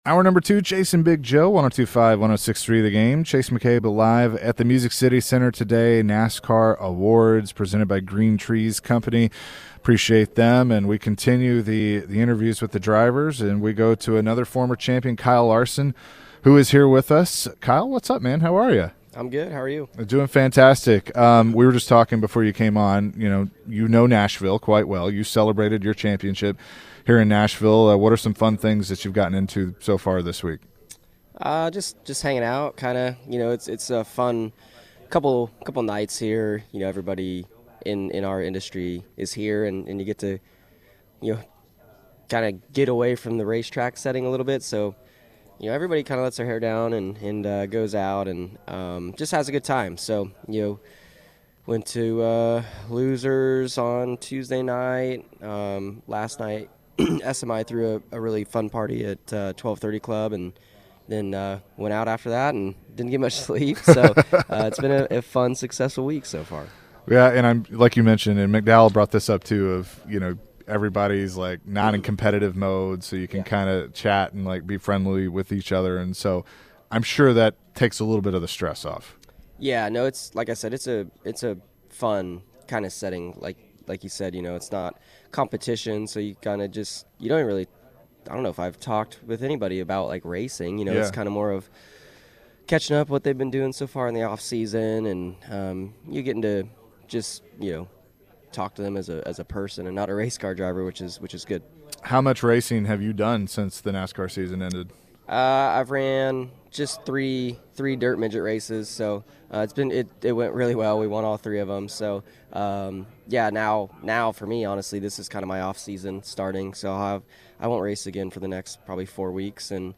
NASCAR Driver Kyle Larson at today's NASCAR Awards celebration.